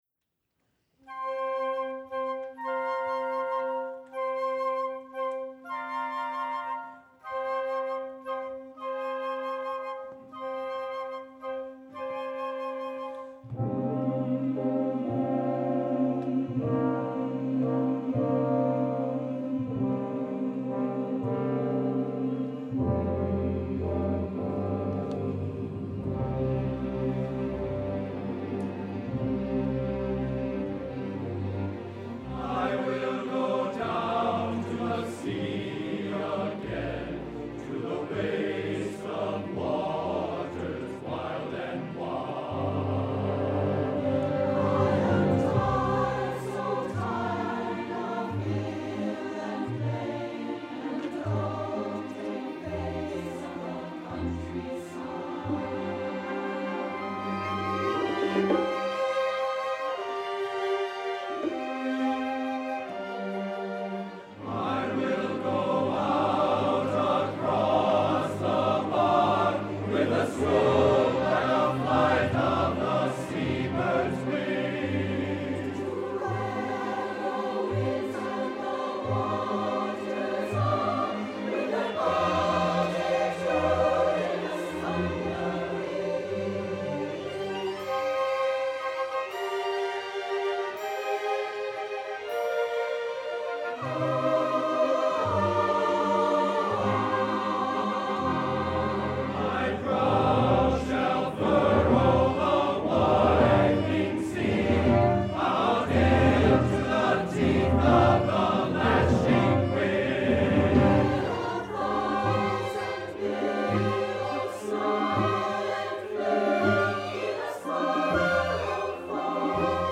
for SATB Chorus and Orchestra (2008)